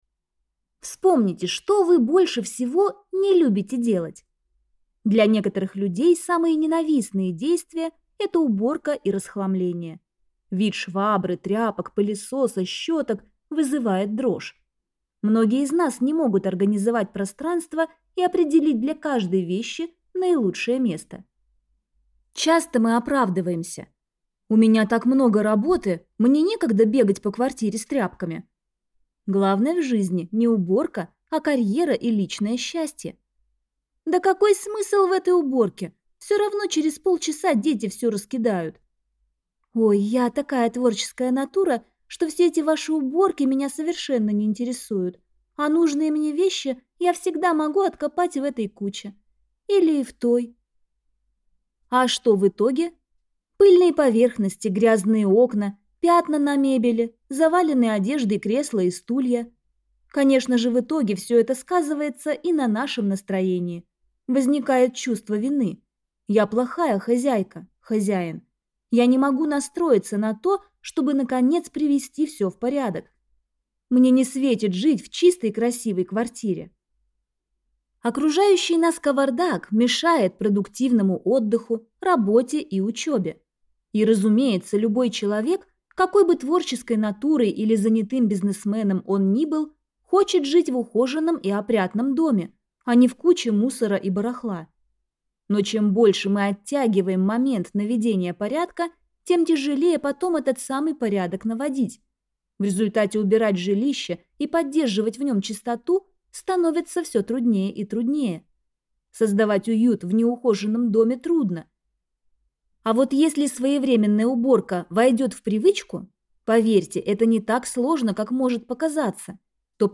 Аудиокнига Магия уютного дома. Вдохновляющая методика наведения порядка без стресса | Библиотека аудиокниг